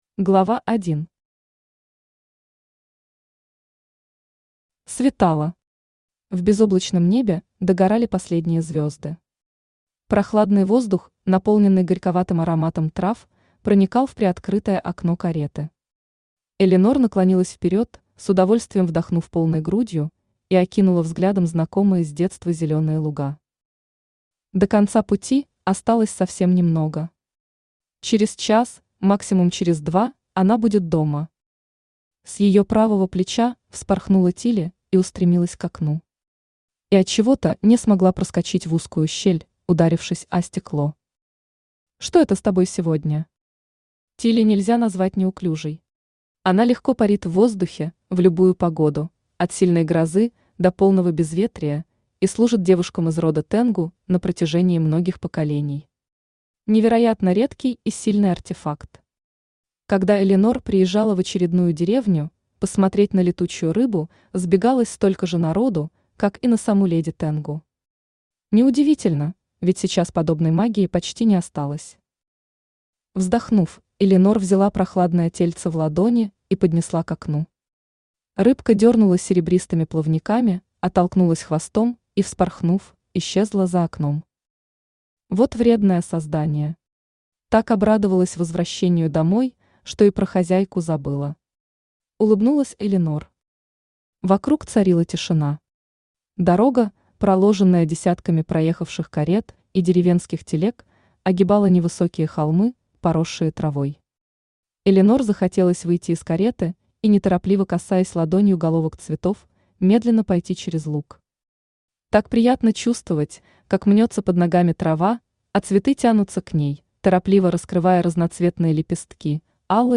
Aудиокнига Драконья мята Автор Татьяна Абиссин Читает аудиокнигу Авточтец ЛитРес.